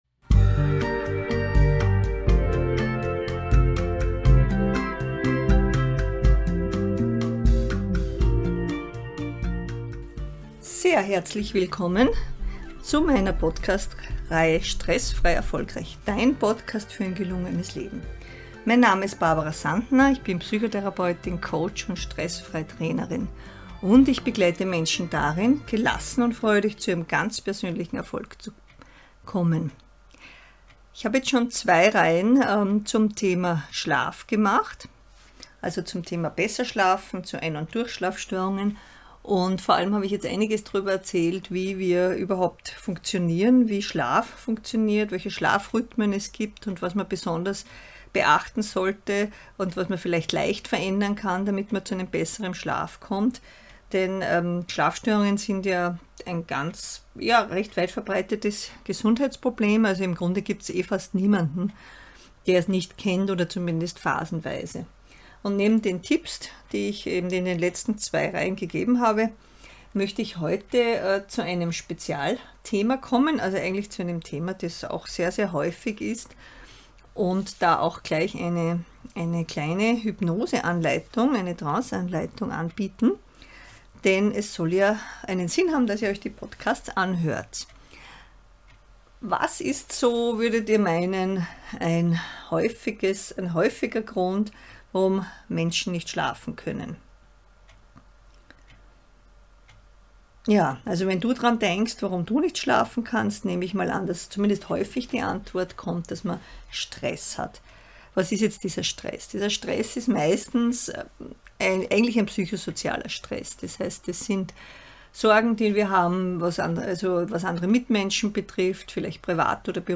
Grübeln und Sorgen machen sind die häufigsten Schlafkiller. In dieser Folge bekommst du eine entspannende Anleitung mit der du die Grübeltrance zur Lösungstrance verändern kannst.